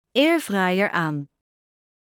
Alle functies van deze airfryer worden uitgesproken met een duidelijke vrouwenstem.